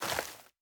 added stepping sounds
DirtRoad_Mono_05.wav